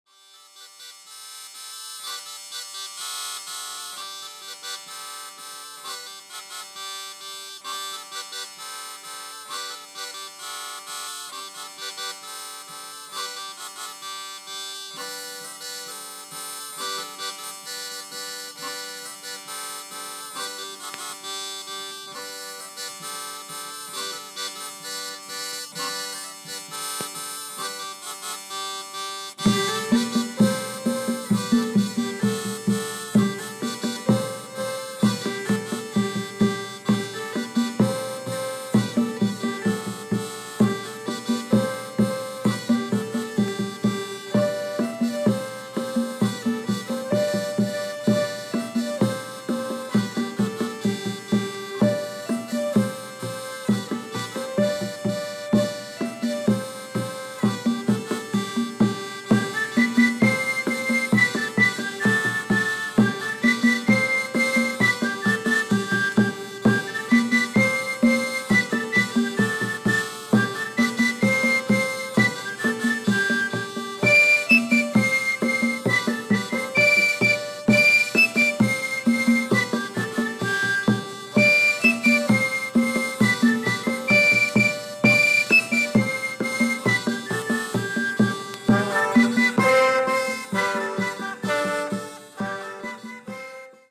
Artist : Les Musiciens De Provence